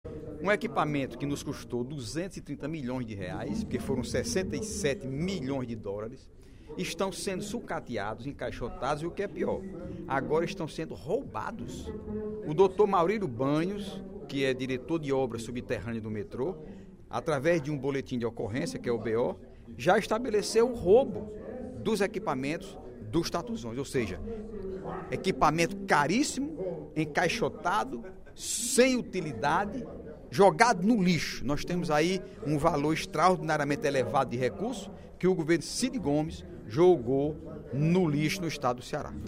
O deputado Heitor Férrer (PSB) apontou, durante a ordem do dia da sessão plenária desta terça-feira (28/03), incoerência no discurso do PT sobre a proposta de Reforma da Previdência.